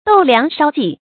斗量筲計 注音： ㄉㄡˇ ㄌㄧㄤˊ ㄕㄠ ㄐㄧˋ 讀音讀法： 意思解釋： 用斗量，用筲計。形容數量很多。